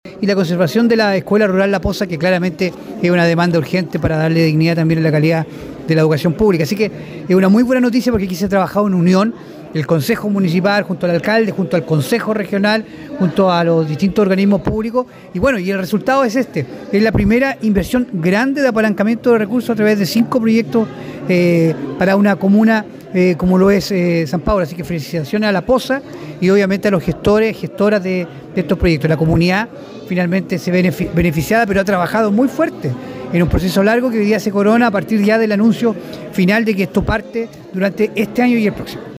El Consejero Regional señaló que la adjudicación de estos cinco proyectos, tiene estrecha relación con el trabajo mancomunado entre municipio, Consejo Regional y organizaciones sociales.